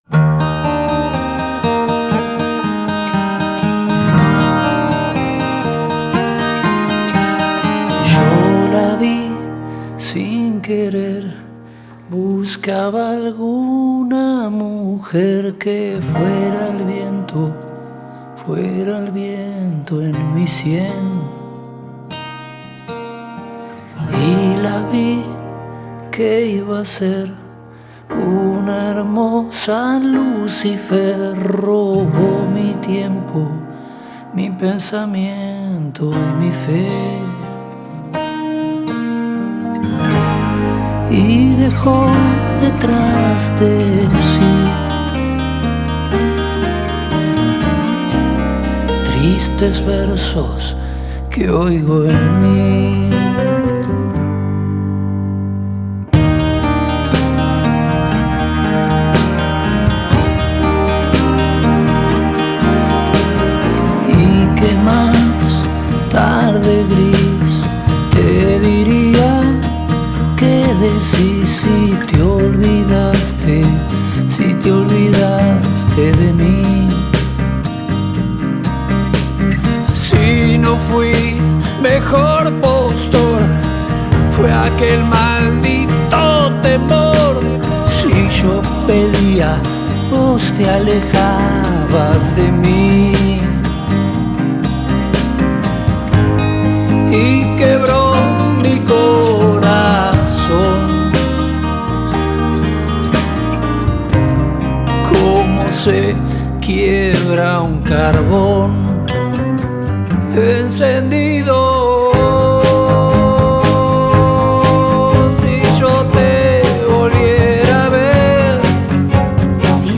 KEupBvcW5kX_insisto-acustico.mp3